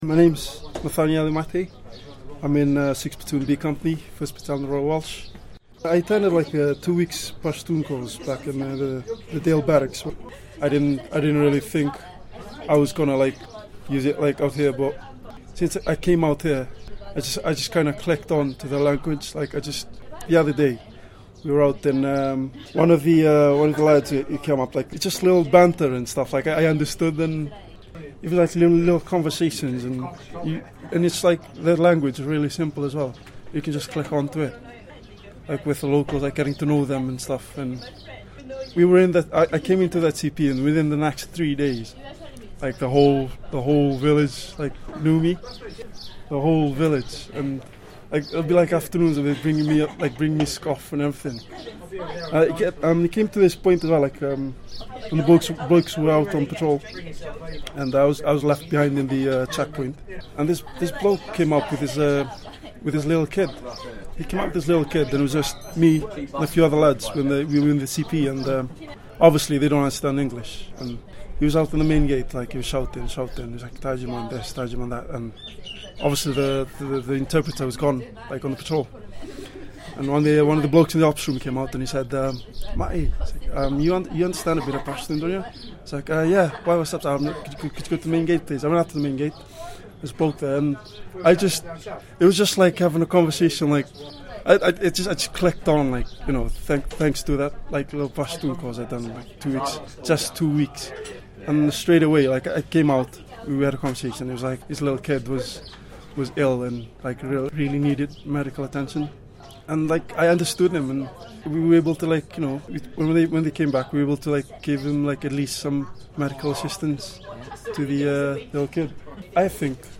The Royal Welsh are lucky to have some awesome Fijians. This particular guy spent a lot of his own time learning the local language (Pashto), and, as he describes, it can prove to be a lifesaver.